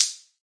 plasticplastic2.ogg